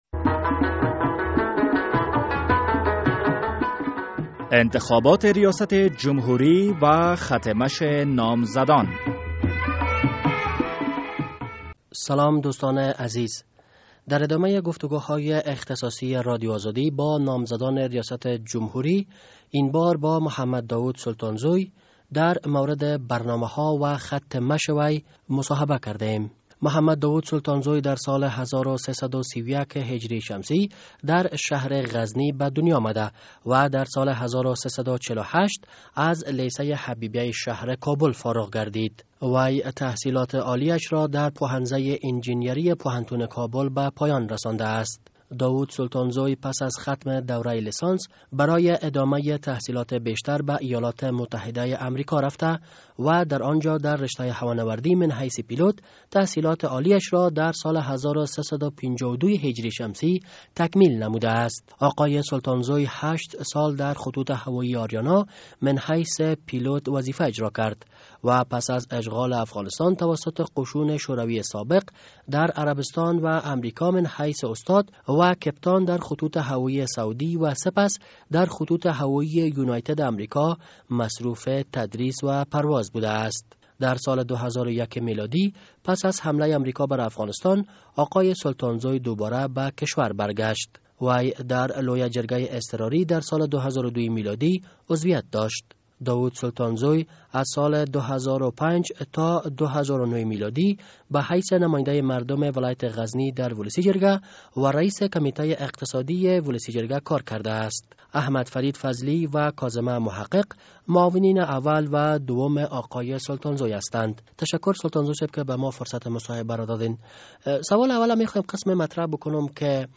مصاحبهء اختصاصی با محمد داوود سلطانزوی